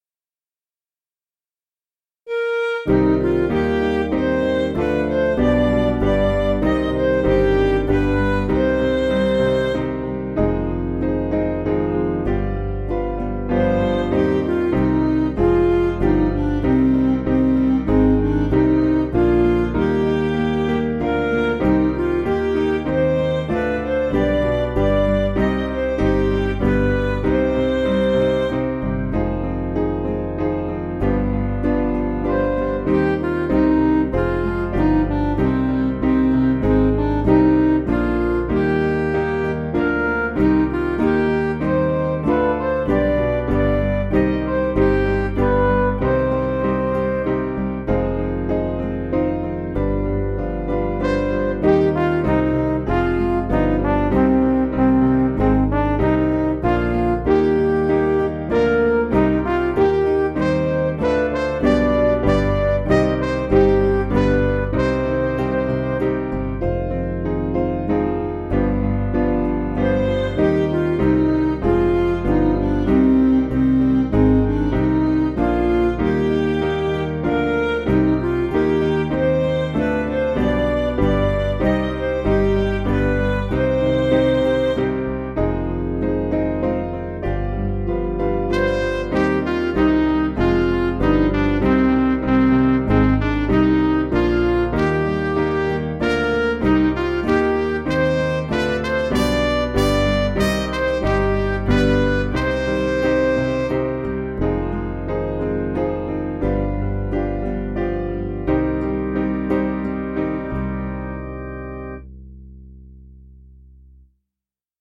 Piano & Instrumental
(CM)   5/Cm
Midi